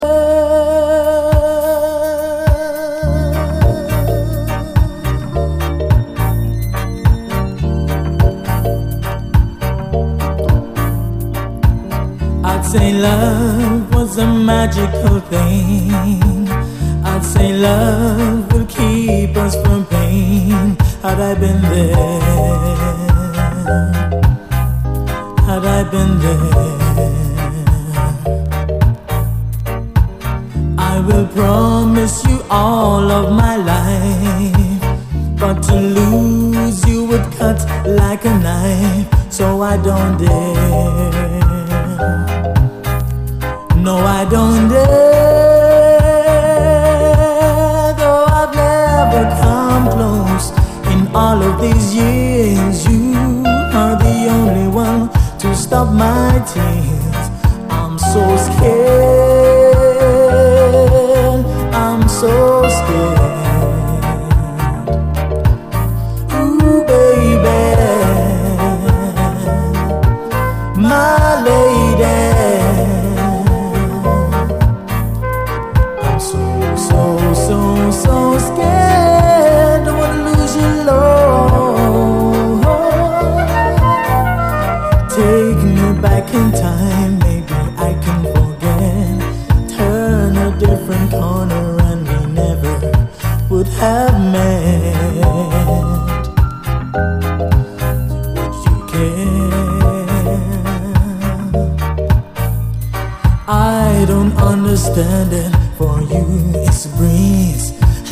REGGAE
ソフト＆メロウなシンセの質感が気持ちいいナイス・チューン！インスト主体でダビーな別ヴァージョンも収録！